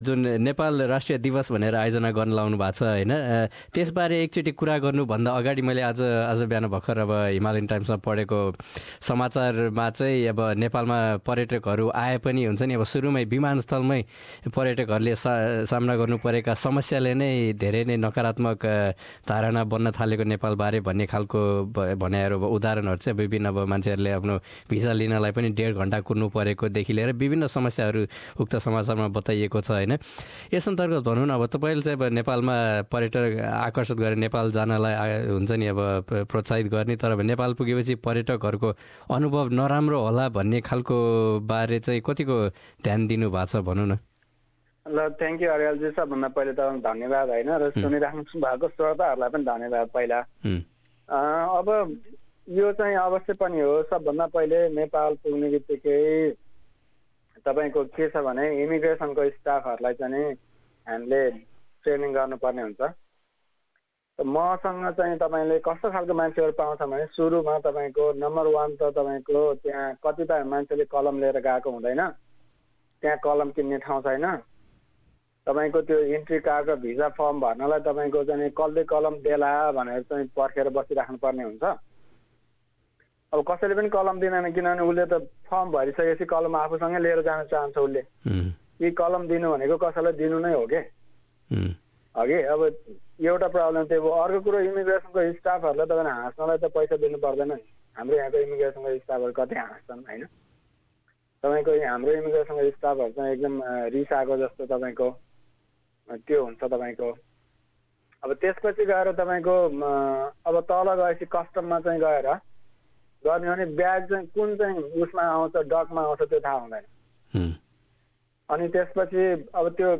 पछिल्ला समयमा नेपालजाने अस्ट्रेलियाई पर्यटकहरुको संख्या लगायतका विषयबारे उहाँले एसबीएस नेपालीसँग गरेको कुराकानी।